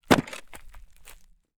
Sand_Pebbles_15.wav